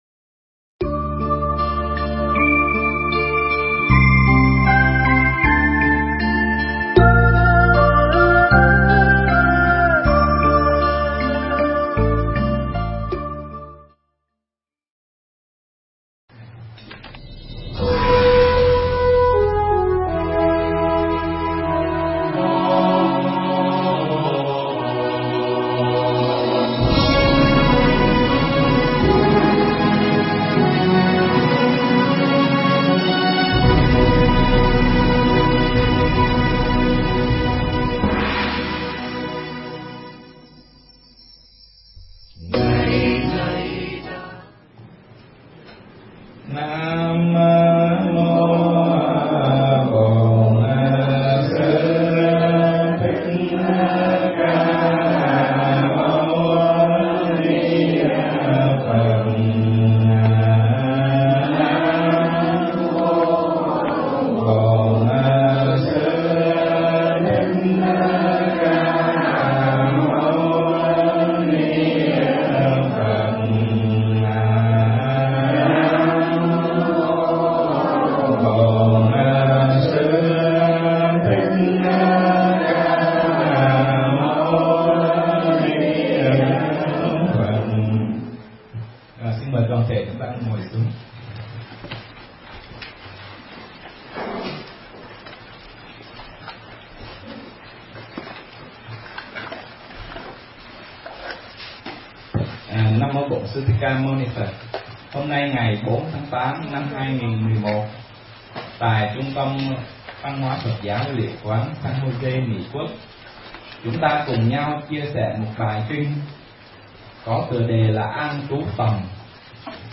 thuyết pháp